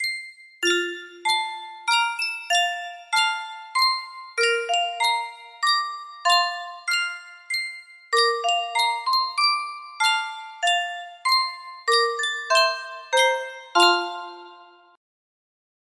Sankyo Spieluhr - Viel Glück und viel Segen 7S music box melody
Full range 60